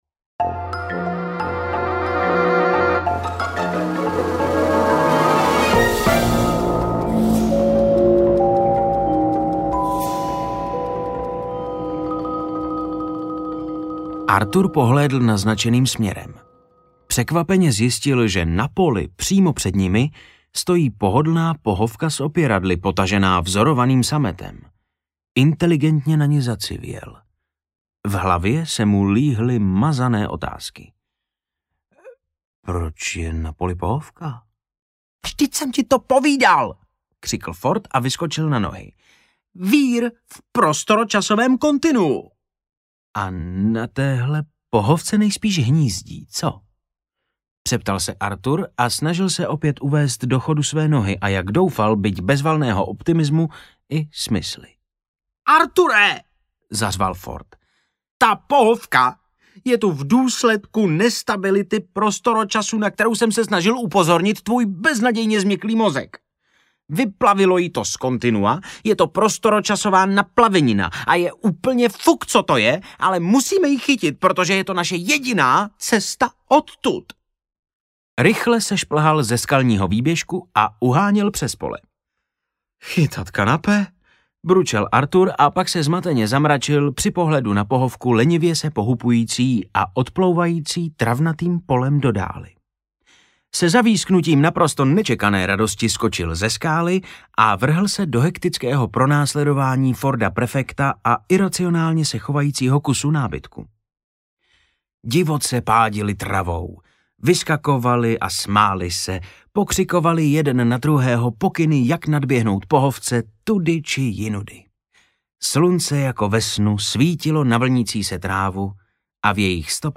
Stopařův průvodce galaxií 3: Život, vesmír a vůbec audiokniha
Ukázka z knihy